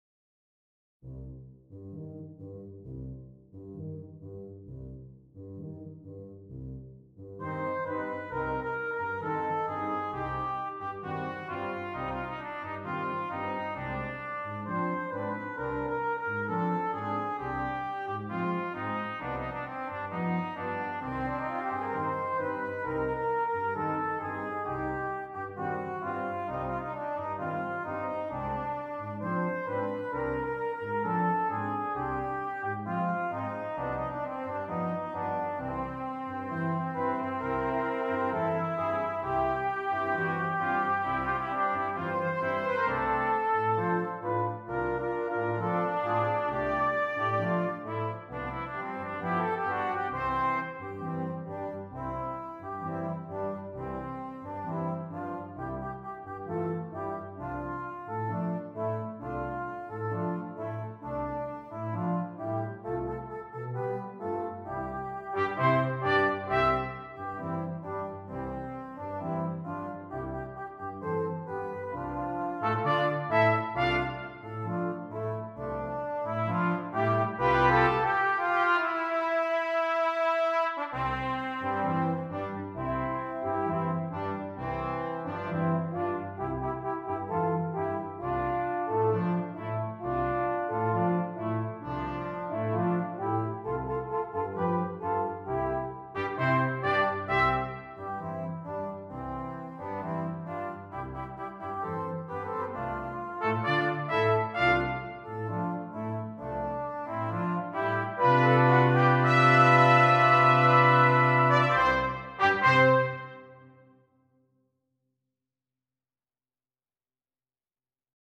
Brass Quintet
Melody passes between the trumpets and horn.